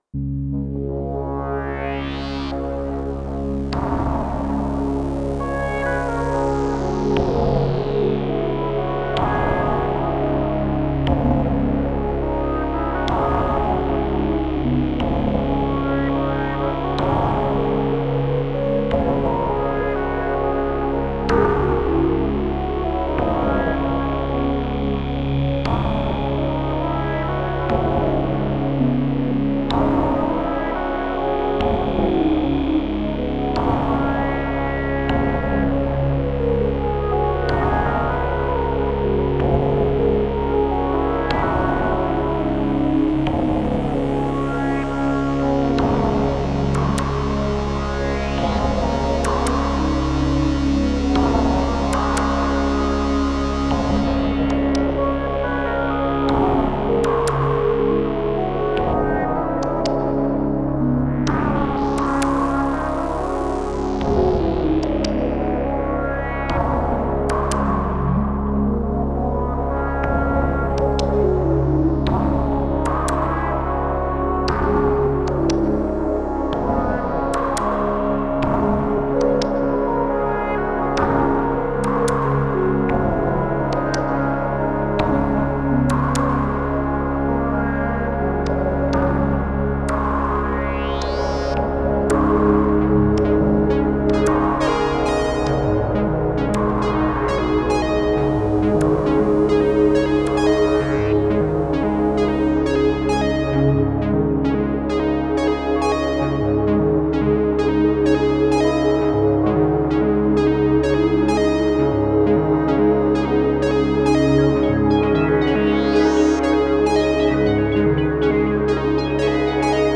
Synthstuff Sequential Circuits SixTrak
For some reason it also sounds really medieval a lot of sounds have this harpish/ hurdygurdy flutish vibe to it which is a pretty cool thing
Listen to some mp3 sounds I recorded from the mighty Sequential SixTrak: (All 100% Sixtrak with some Behringer delay reverb)